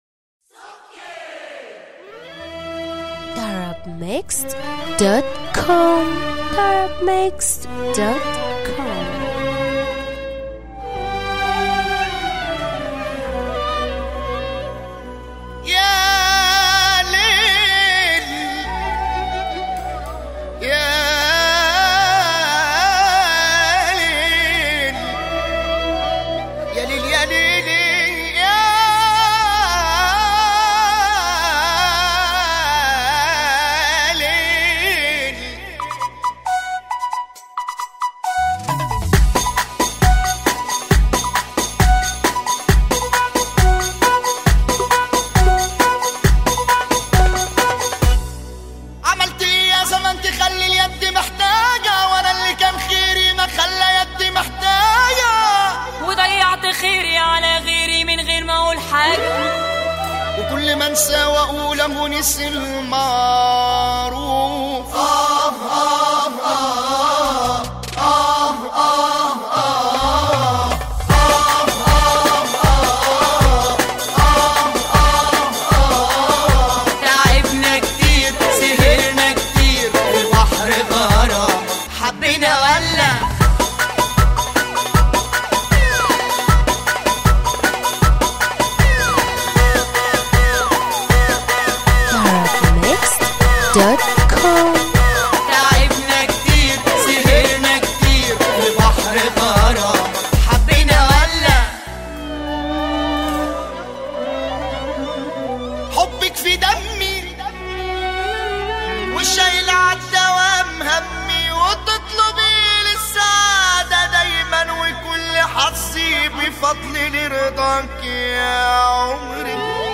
اغانى شعبى